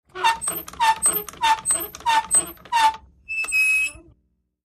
MACHINES - CONSTRUCTION HAND MANUAL WATER PUMP: INT: Quick pumping, quick mechanism squeaks, wood stress.